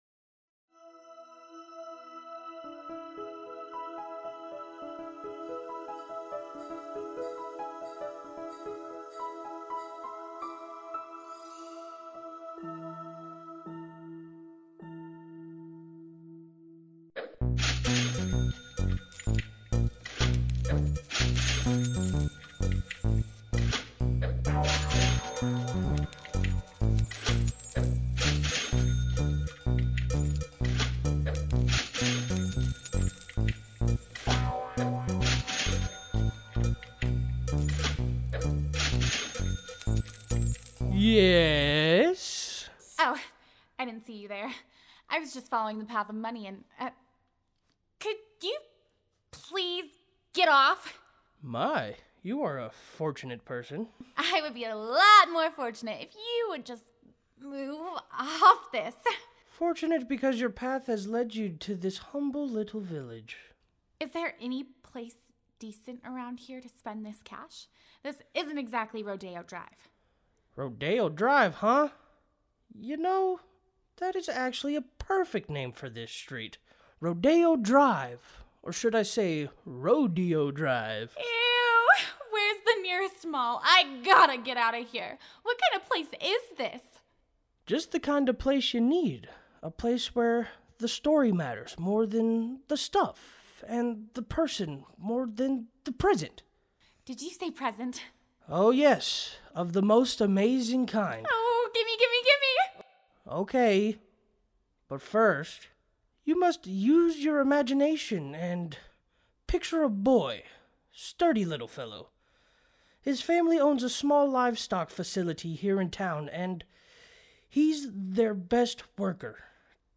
Sneak Preview –  Listen to these samples of the Audio Performances you will get with the Helpful Production Bundle for Just My Imagination.